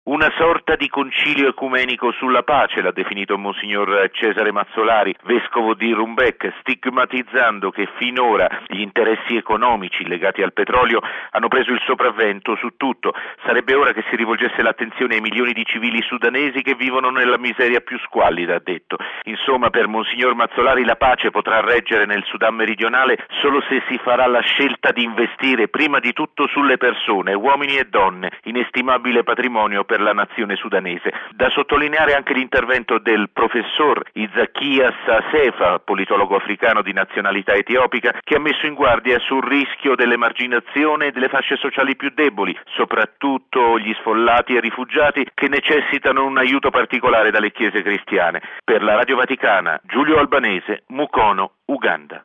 Questo il pensiero dell’arcivescovo sudanese di Juba, Mons. Paolino Lukudu, uno dei 70 delegati presenti all’incontro ecumenico organizzato a Mukono, in Uganda, dalle Chiese cristiane del Sudan. Da Mukono